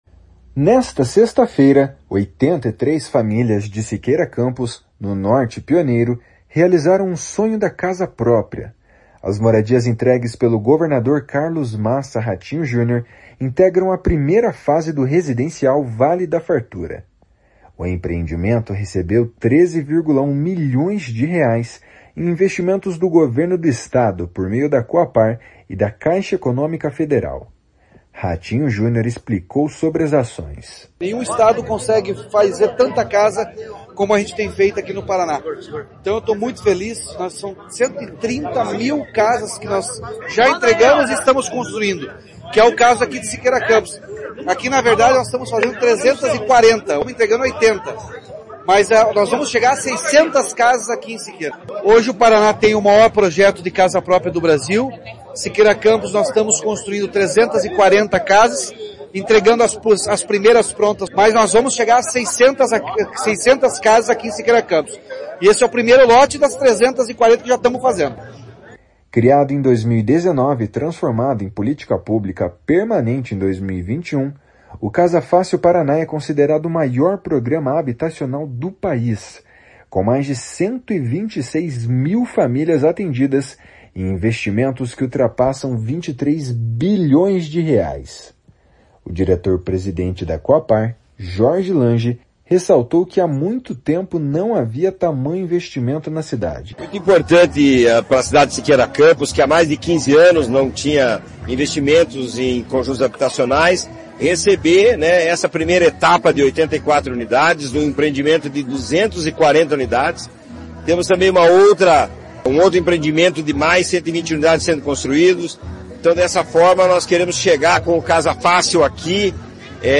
Ratinho Junior explicou sobre as ações. // SONORA RATINHO JUNIOR //
O prefeito de Siqueira Campos, Luiz Henrique Germano, destaca a importância da ação para tantas pessoas.